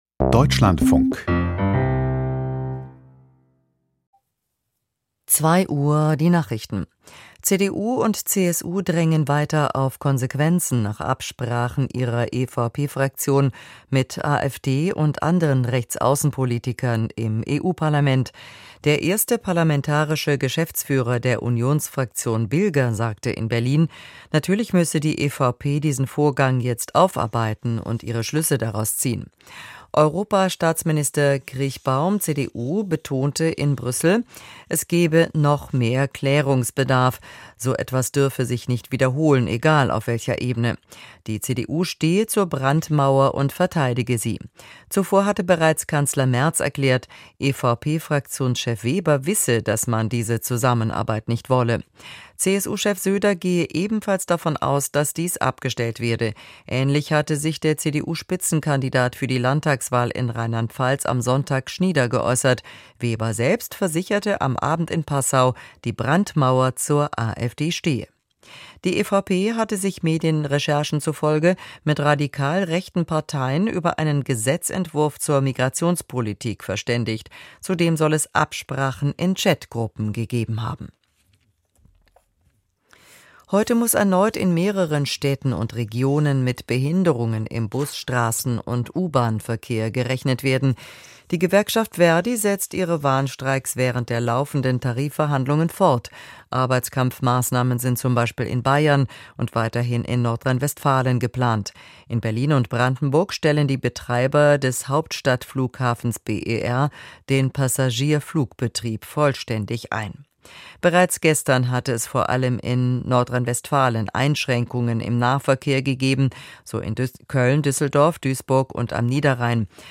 Die Nachrichten vom 18.03.2026, 02:00 Uhr
Aus der Deutschlandfunk-Nachrichtenredaktion.